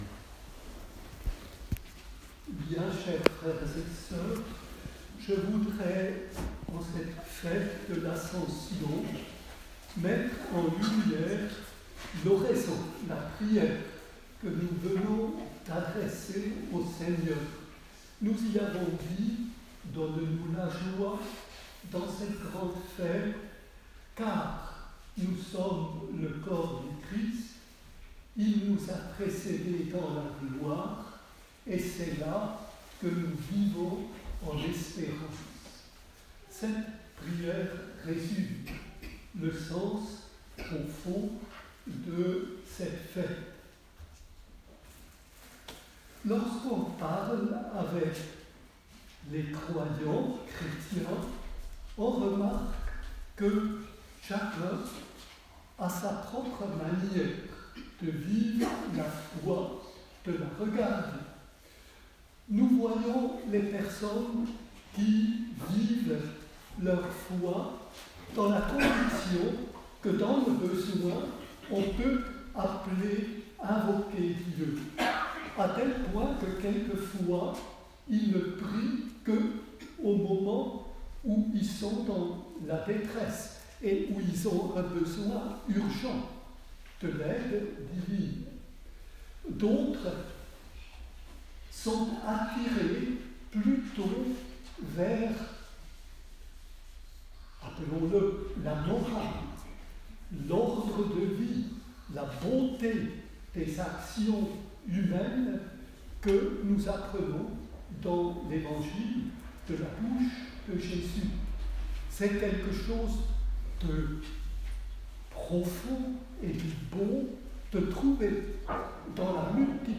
Ci-dessous, un enregistrement audio de son homélie à la messe solennelle de ce matin pour célébrer l'Ascension du Seigneur.